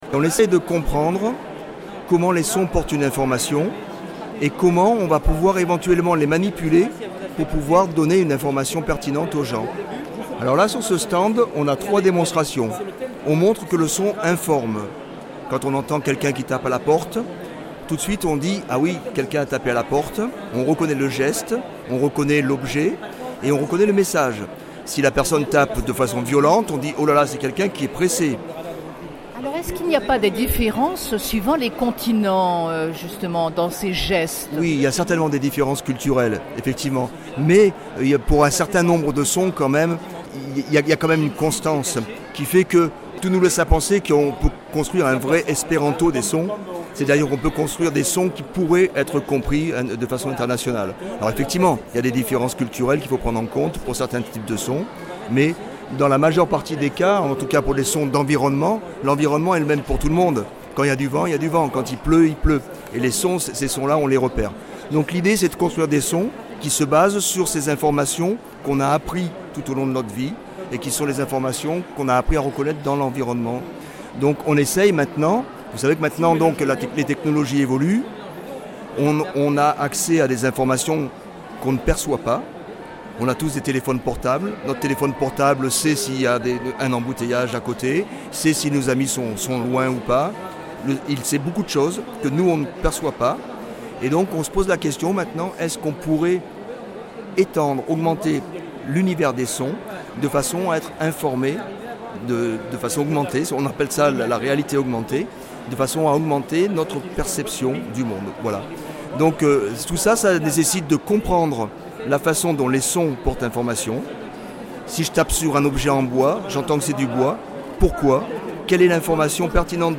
Nous sommes à l’entrée de la Nuit Européenne des Chercheurs 2016, organisée par Aix Marseille Université (AMU).